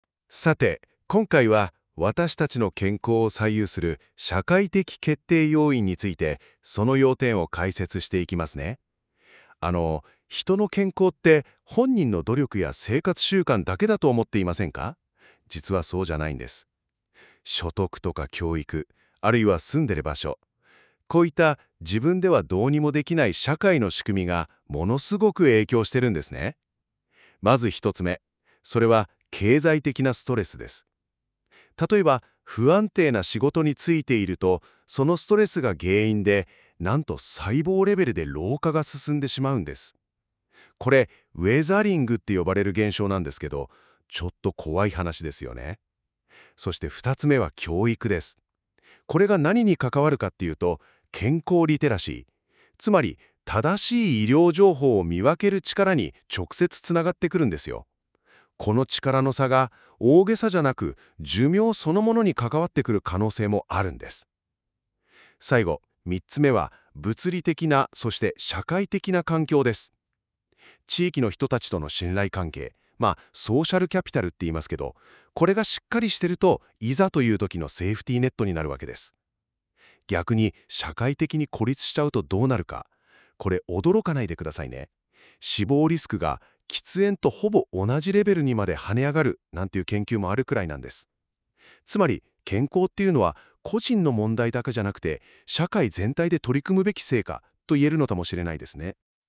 音声による概要解説